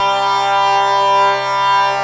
LA ZANFONA
zanfona.wav